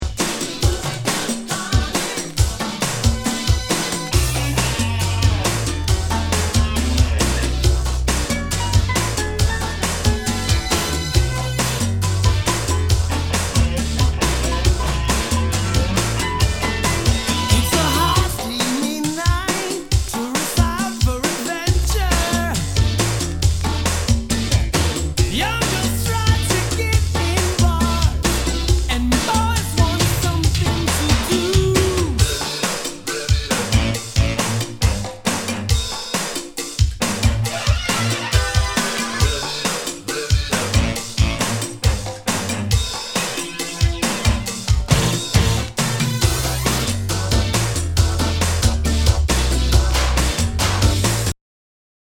SOUL/FUNK/DISCO
ナイス！ラテン・シンセ・ポップ・ディスコ！